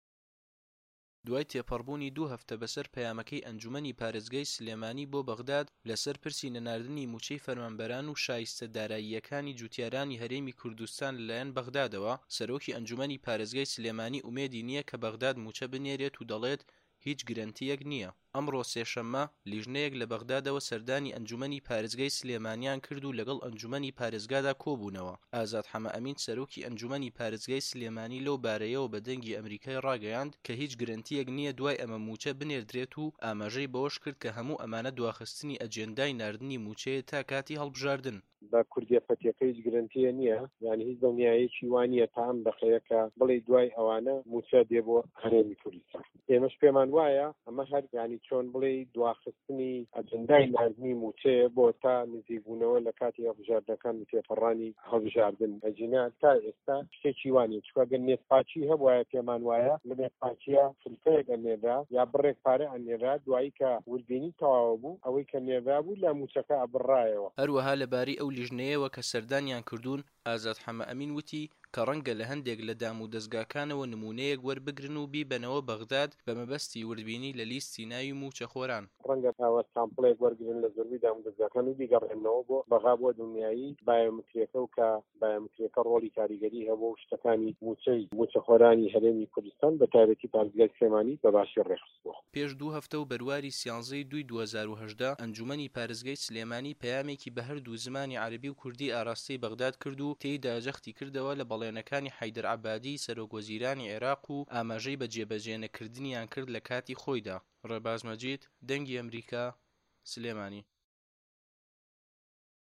ڕاپـۆرتی